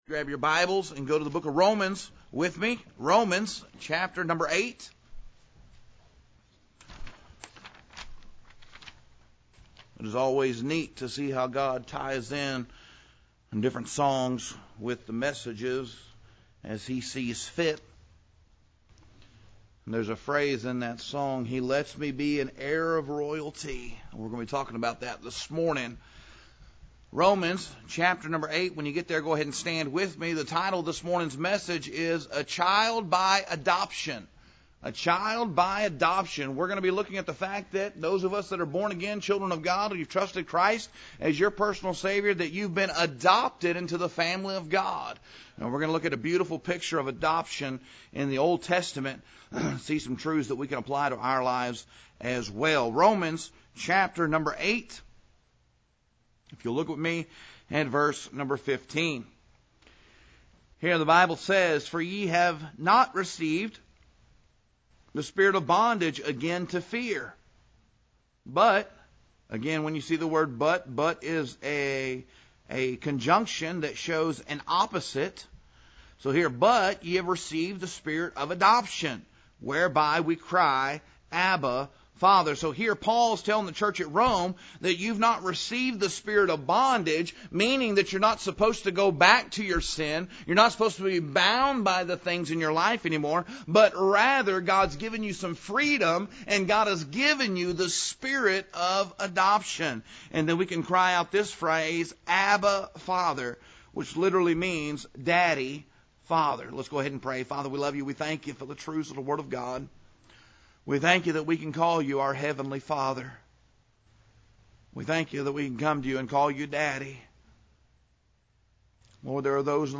Today’s sermon title is “A Child By Adoption.” The text centers on Romans chapter 8 and the biblical truth that everyone who trusts Jesus Christ as Saviour is not only forgiven but legally and spiritually adopted into God’s family.